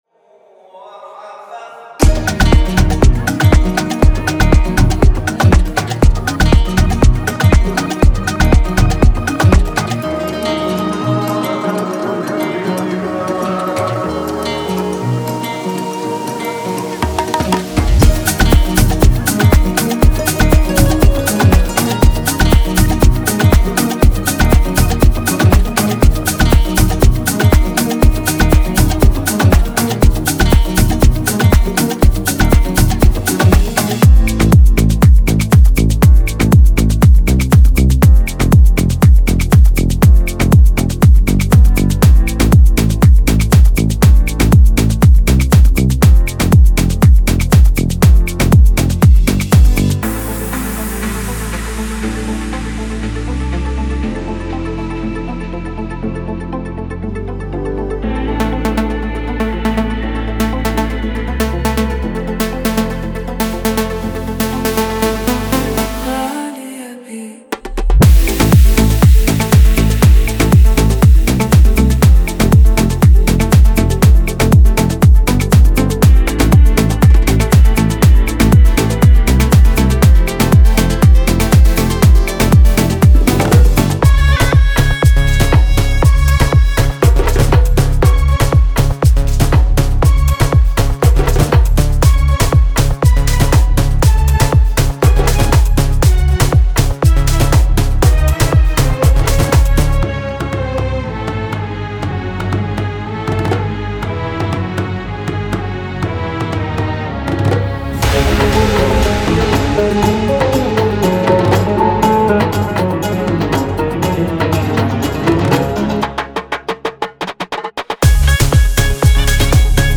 Genre:Afro House
中東の伝統楽器（ドゥドゥク、ウード、カマンジャ、ダルブカなど）を文化的ニュアンスと表現技法を尊重した本格的な演奏で収録
アフリカンインフルエンスのパーカッションパターンとグルーヴでアフロハウスのリズム基盤を表現
ピアノ、フルート、ギター、ディープベース、リース、メロディックシンセ、表現力豊かなボーカルなどの補完要素
ご注意：デモ音源は大音量でコンプレッションがかかり均一化されています。
120 BPM